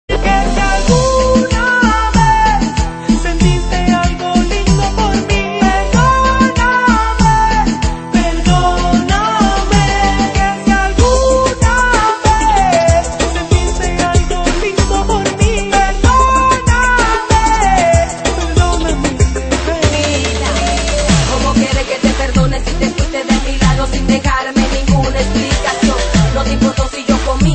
• Latin Ringtones